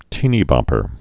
(tēnē-bŏpər)